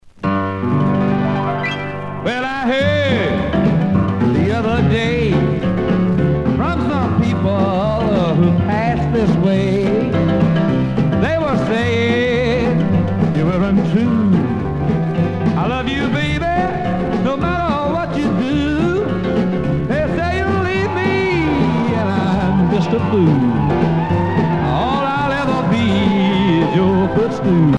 Fonction d'après l'analyste danse : rock ;
Catégorie Pièce musicale éditée